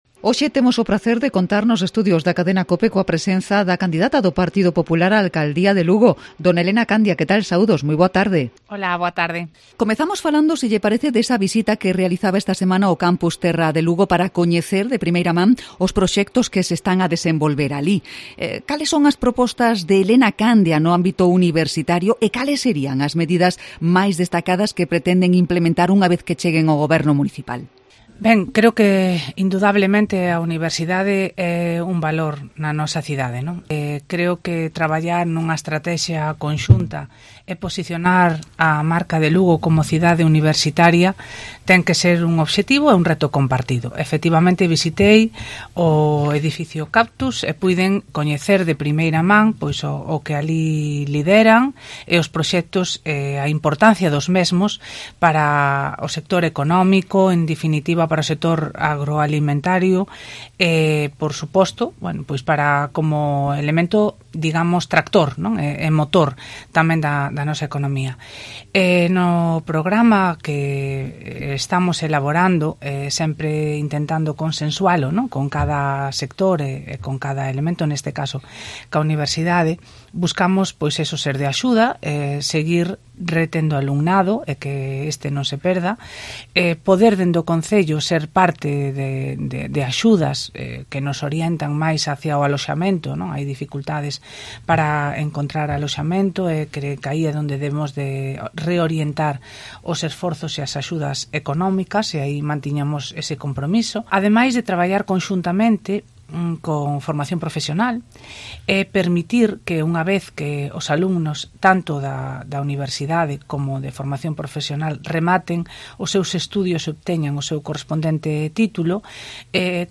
Entrevista a Elena Candia en Cope Lugo